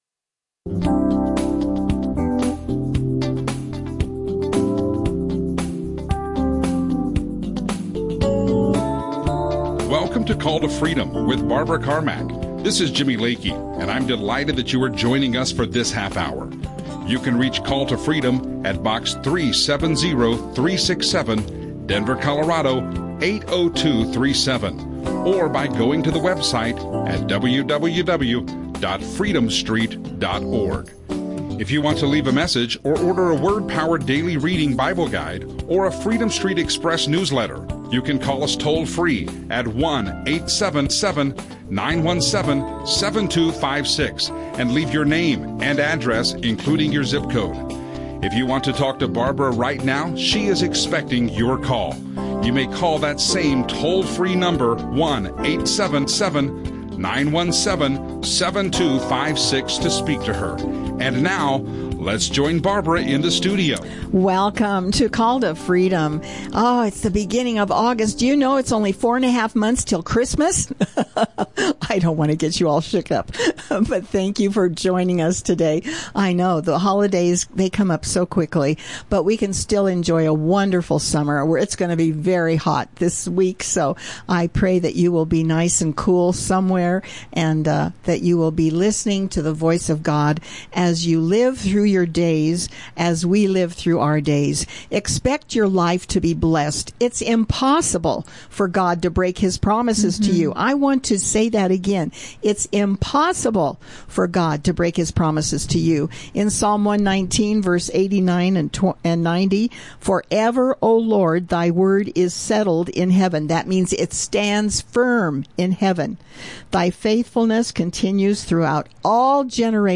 Christian radio show